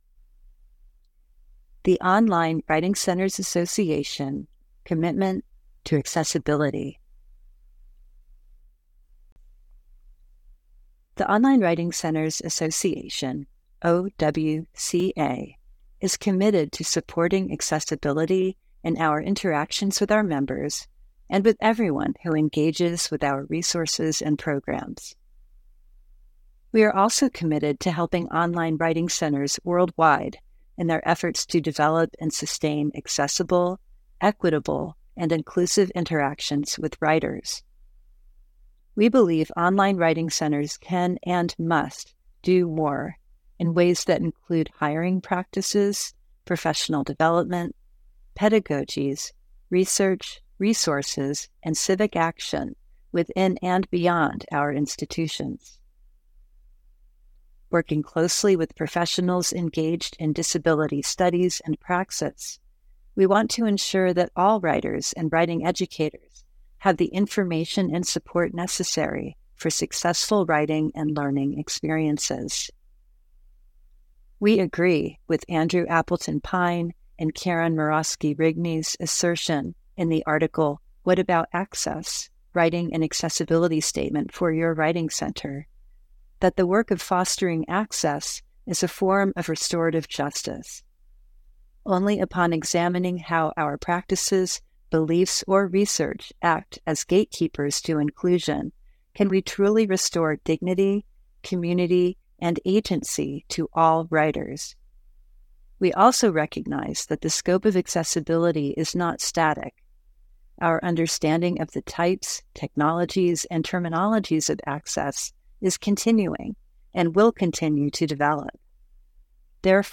The text of the Accessibility Statement is also available in audio format via the embedded media player: